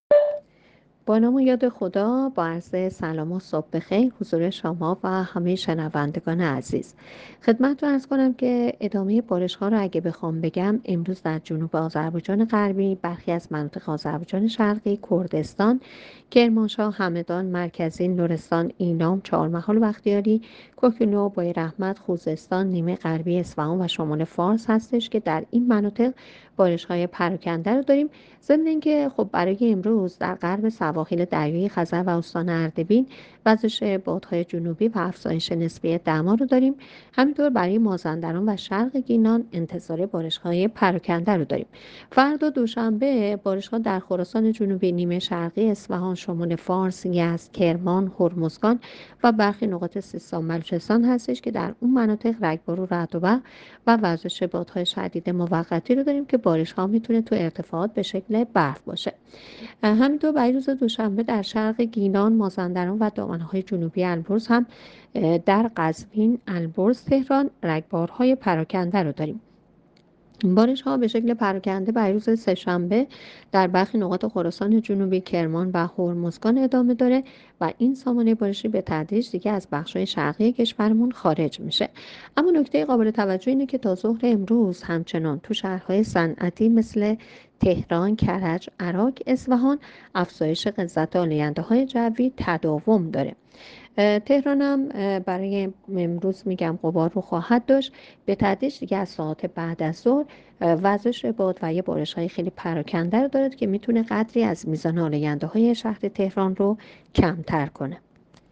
گزارش رادیو اینترنتی پایگاه‌ خبری از آخرین وضعیت آب‌وهوای ۲۳ دی؛